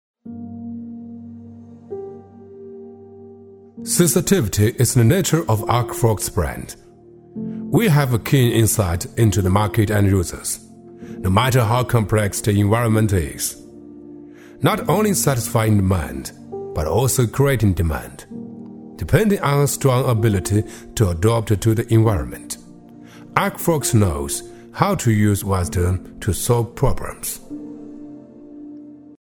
男369-英文【素人自然】
男369-中英双语 大气浑厚
男369-英文【素人自然】.mp3